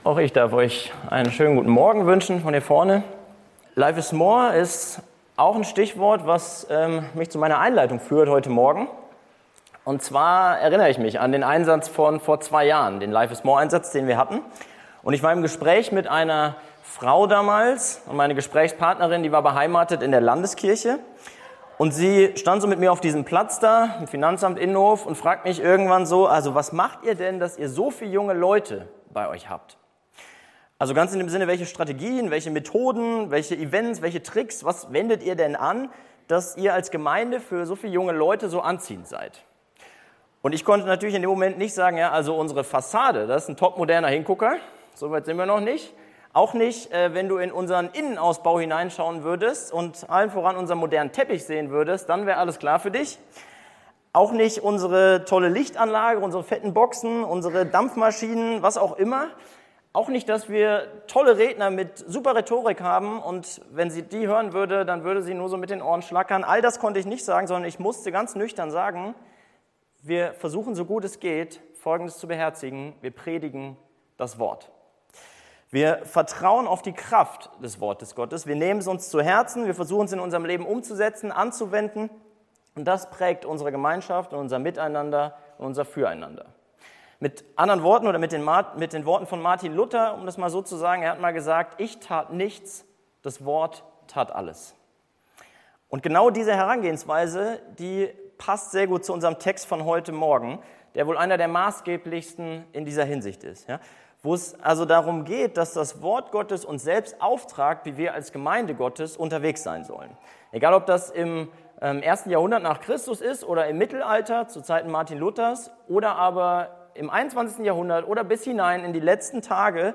predigte über 2. Timotheus 4,1-8 mit den Unterpunkten